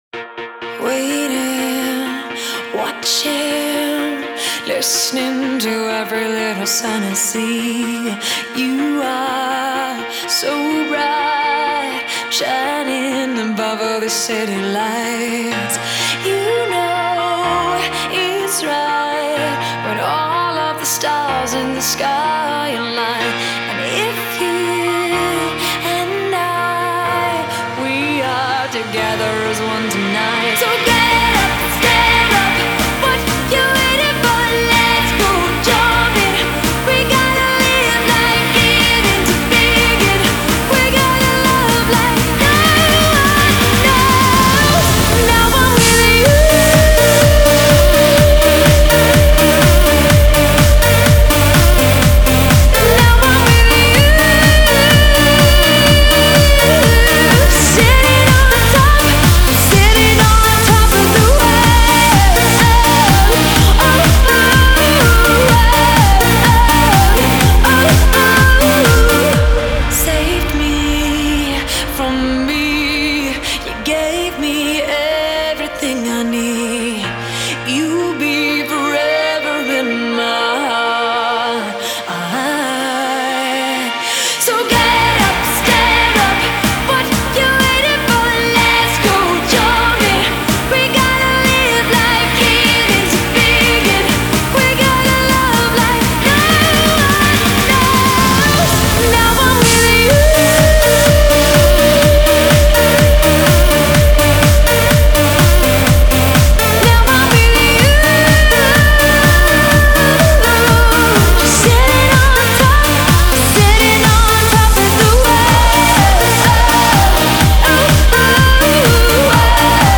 Жанр: Pop; Битрэйт